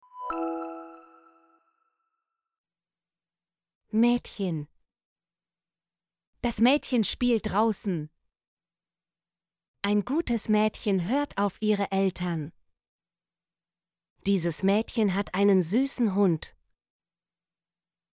mädchen - Example Sentences & Pronunciation, German Frequency List